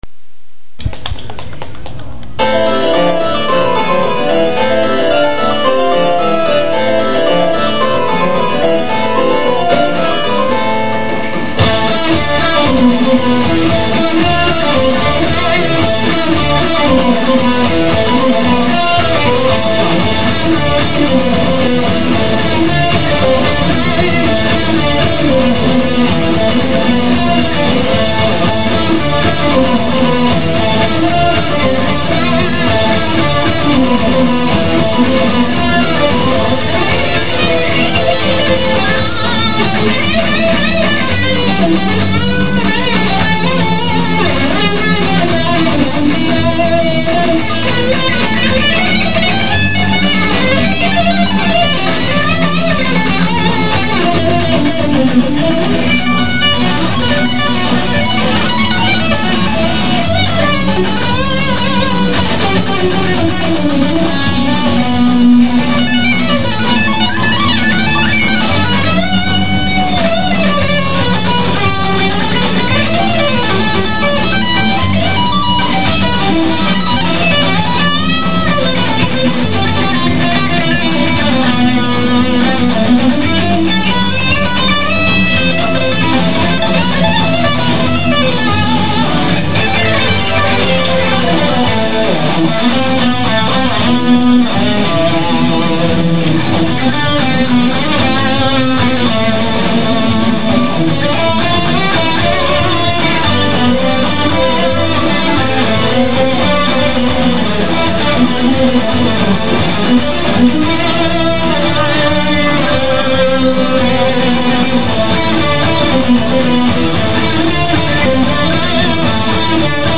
(東京大学駒場祭2003　ＳＨＫライブより)
中盤のブラジリアンな感じのところのソロは